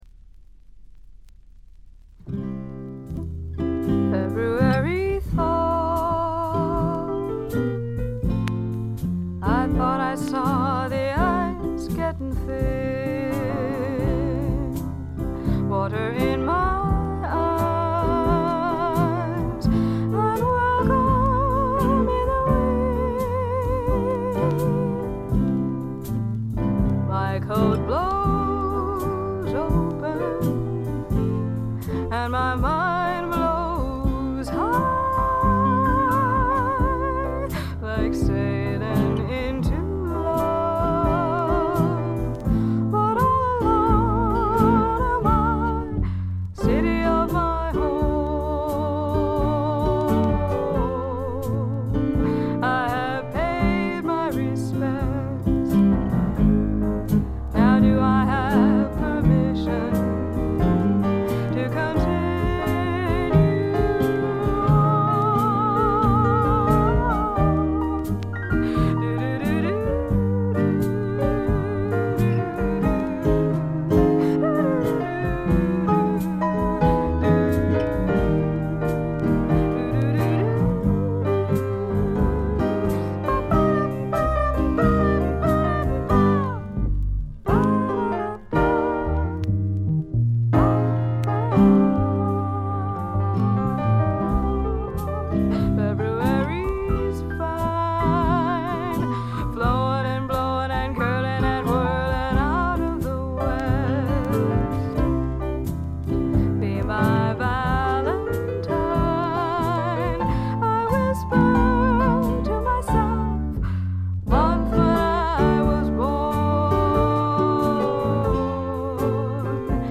ホーム > レコード：米国 女性SSW / フォーク
弾き語りを中心にごくシンプルなバックが付く音作り、トラッドのアカペラも最高です。
Vocals, Guitar, Autoharp, Recorder